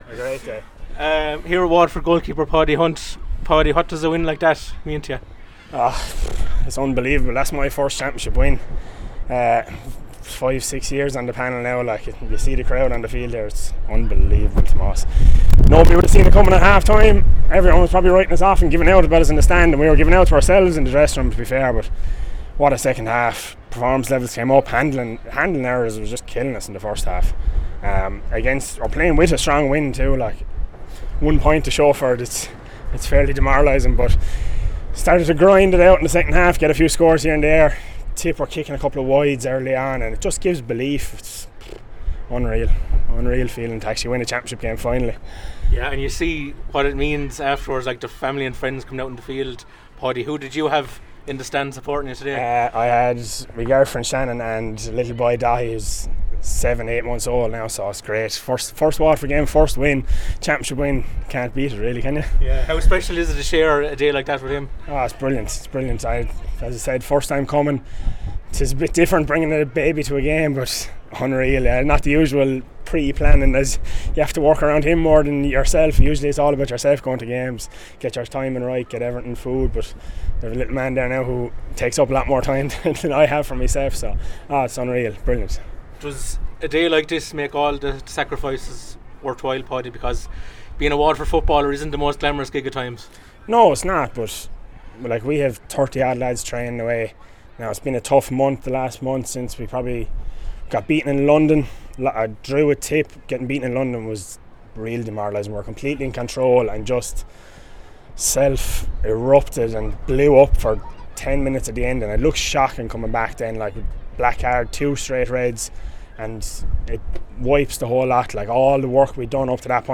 spoke to WLR Sport after the game